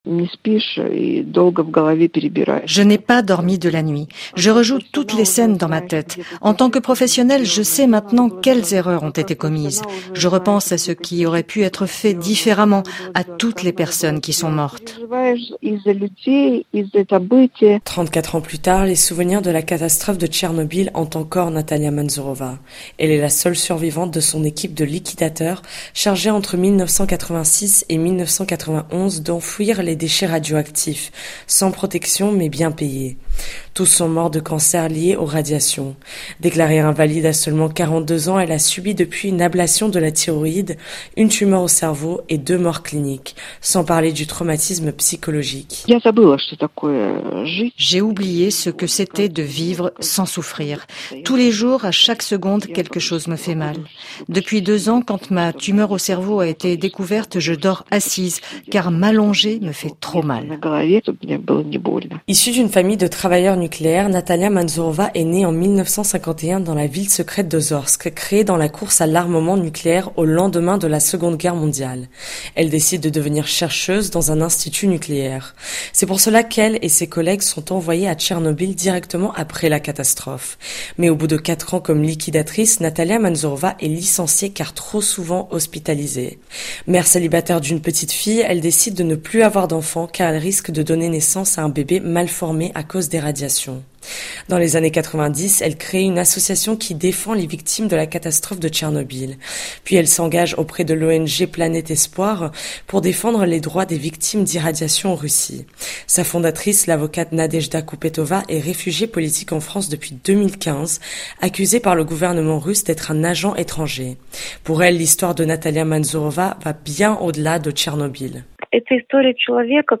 Dans cette archive sonore, des survivants reviennent sur leur vécu :